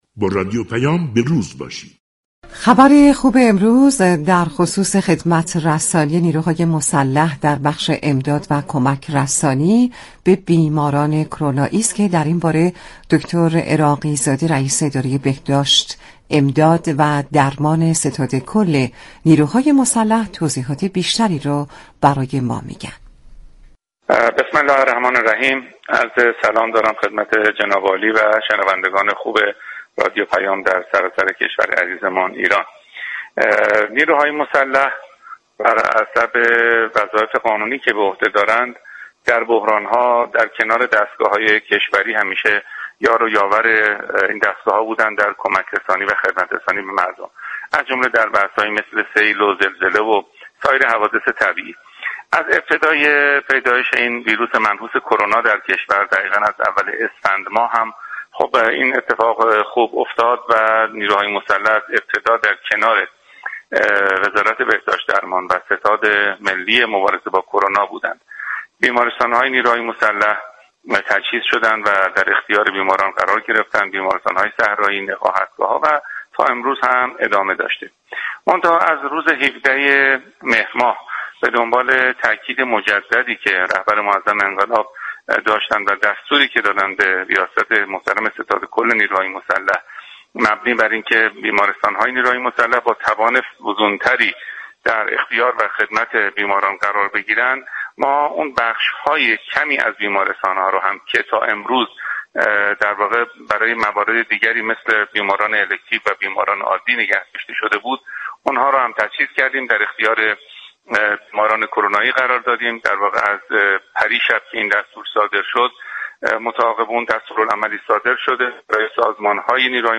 دكتر عراقی‌زاده،رئیس اداره بهداشت و درمان ستاد كل نیروهای مسلح در گفتگو با رادیو پیام ،جزئیات اقدامات مجموعه بهداشت نیروهای مسلح در مقابله با ویروس كرونا را بازگو كرد .